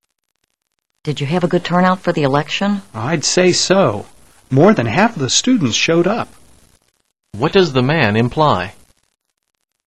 What does the man imply?